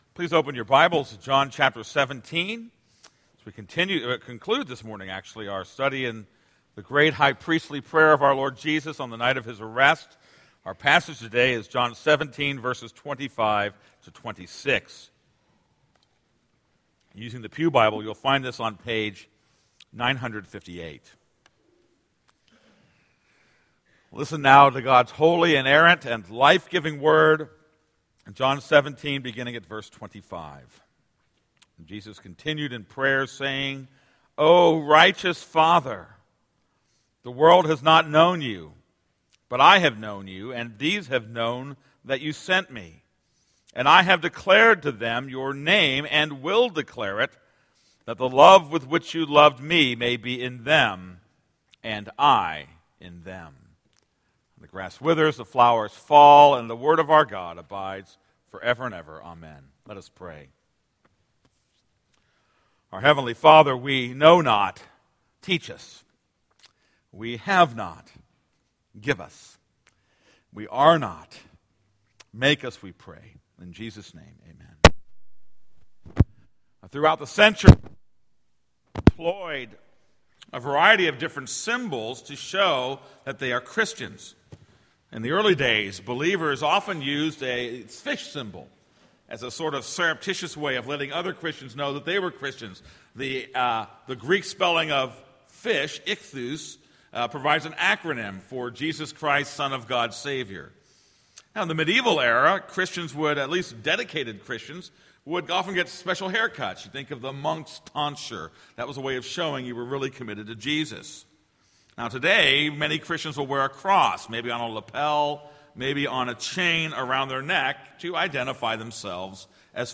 This is a sermon on John 17:25-26.